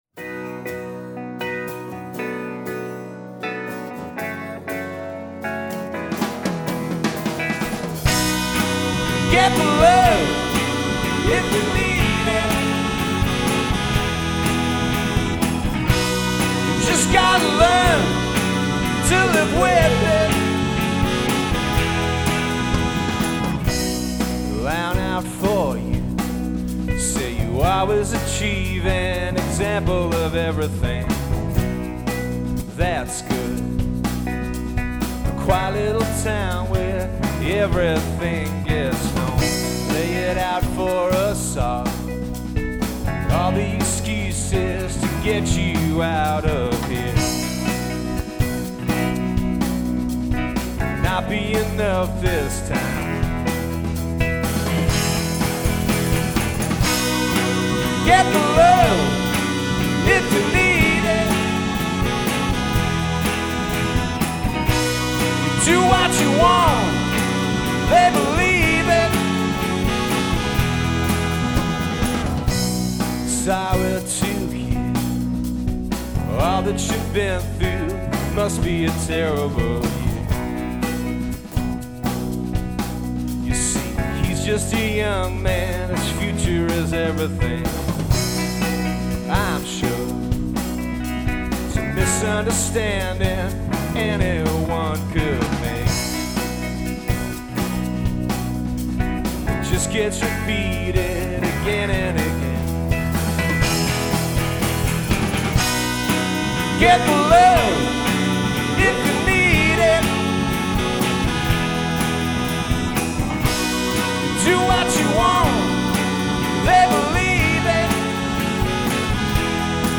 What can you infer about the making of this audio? Each of the acts recorded live versions of their entries.